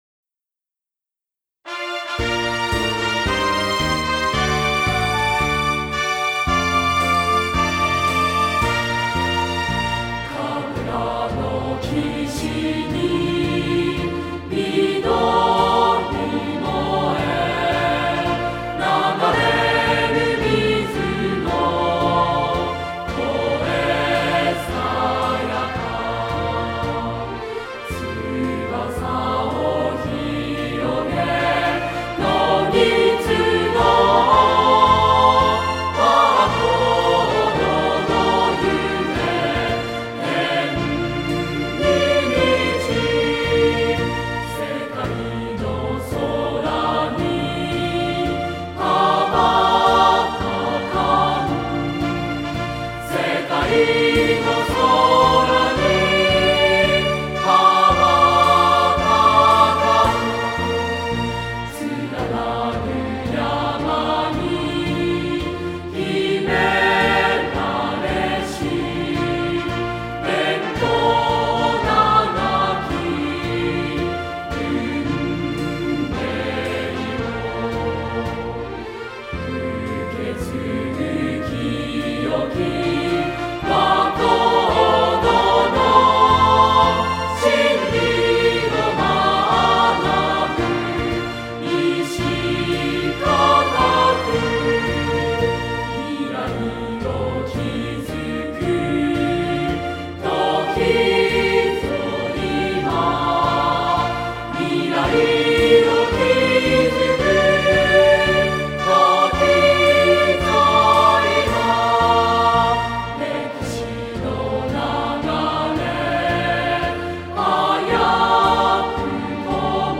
校歌